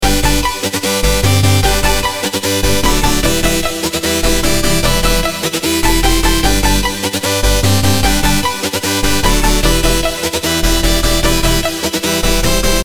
FUTURE BASS SAMPLE PACK
3 Gmaj – Starter Loop